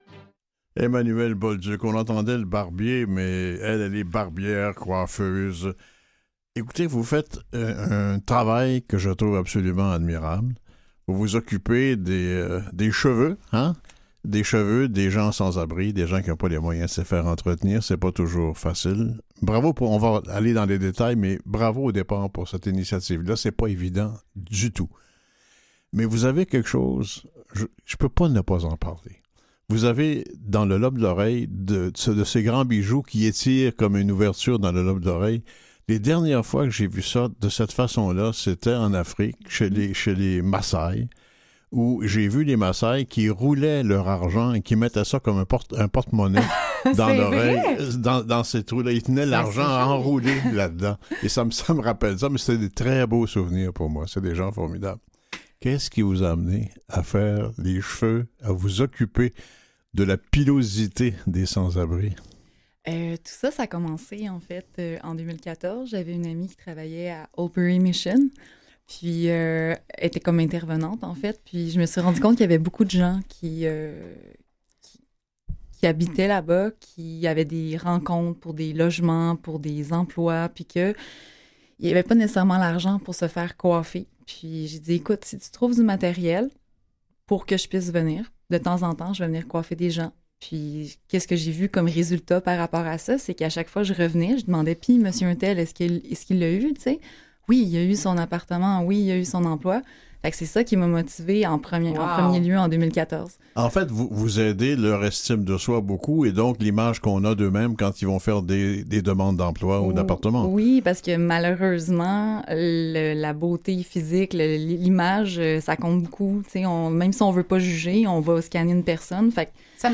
Voici le lien de l'entrevue 💙🙌 https